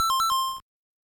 Pause sound effect from Super Mario Bros. 3